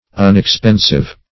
Unexpensive \Un`ex*pen"sive\, a.